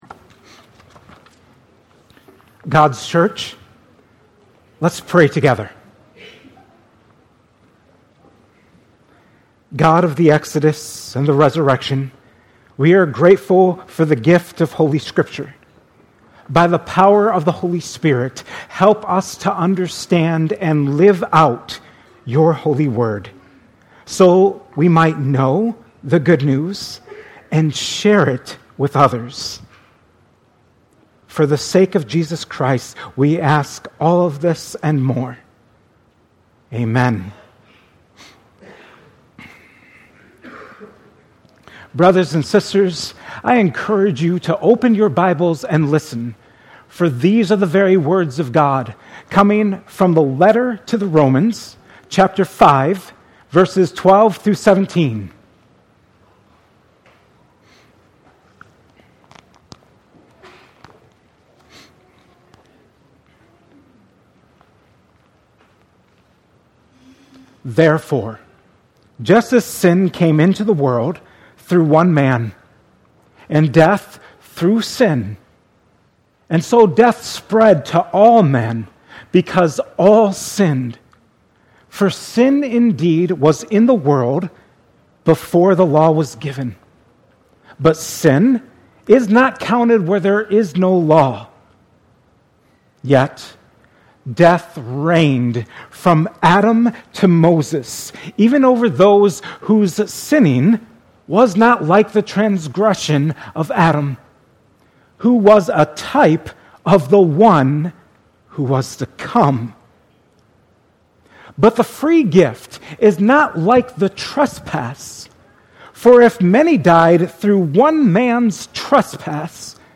at Cornerstone Church on February 9, 2025.